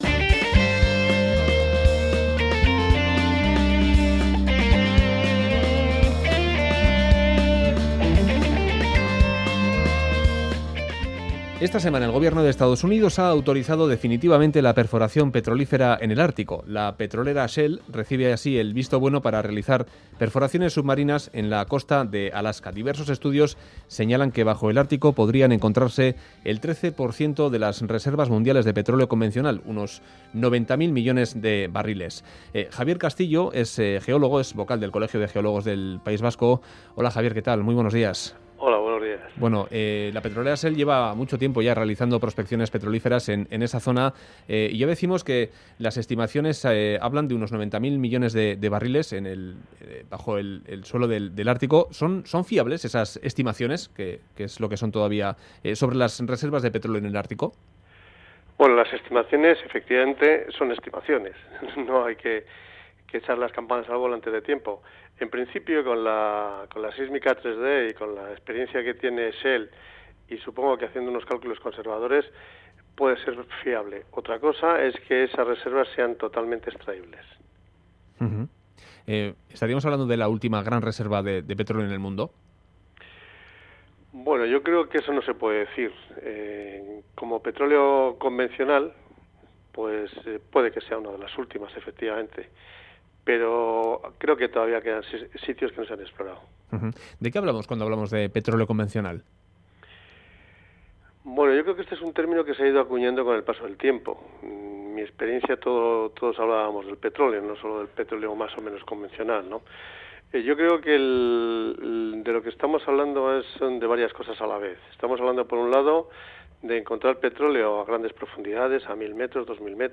Artico : dos entrevistas sobre las prospecciones petrolíferas en Alaska y su impacto en el ecosistema ártico.